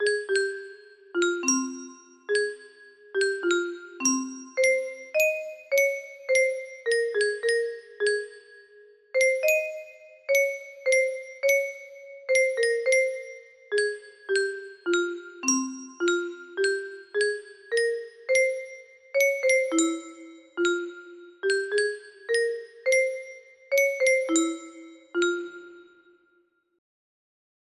Kossuth Lajos music box melody